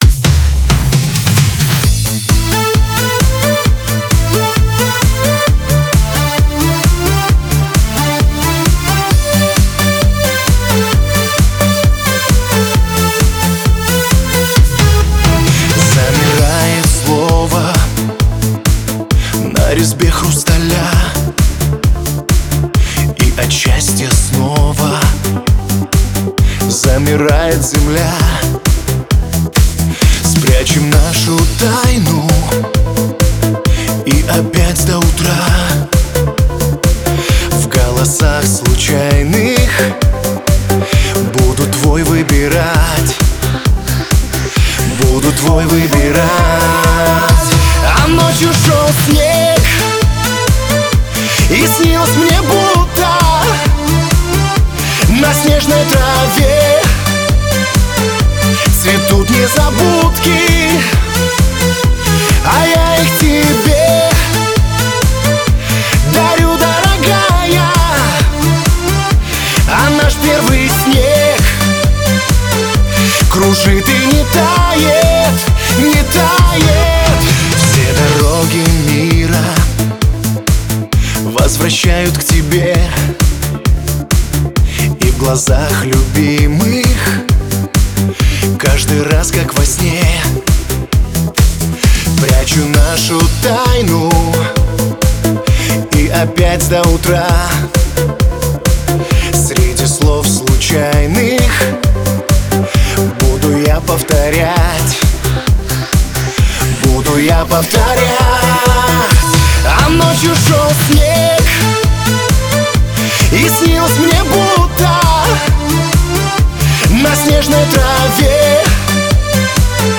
Пластинка в стиле русскоц поп музыки!